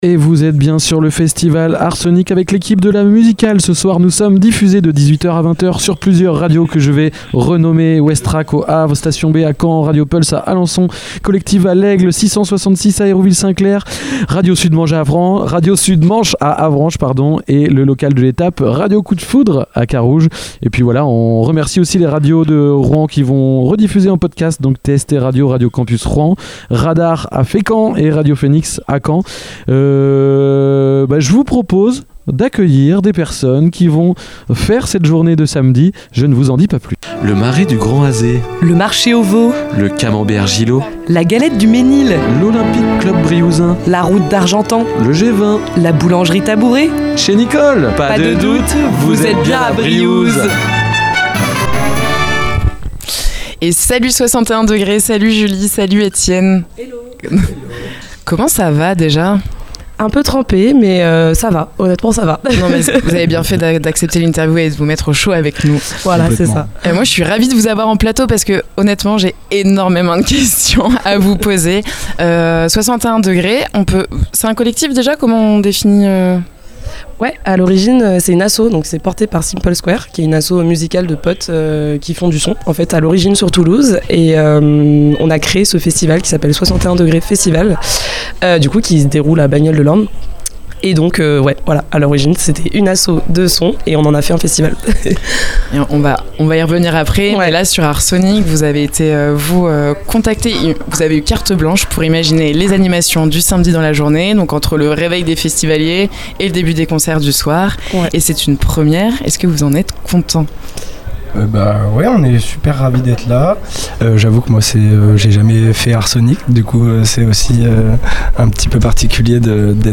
Dans cette interview réalisée dans le cadre de l’émission spéciale enregistrée au festival Art Sonic à Briouze, les radios de l’Amusicale — Ouest Track, Station B, PULSE, Kollectiv’, 666, Radio Sud Manche, Radio Coup de Foudre, Radar, Phénix, Radio Campus Rouen et TST Radio — sont parties à la rencontre des artistes qui font vibrer le festival.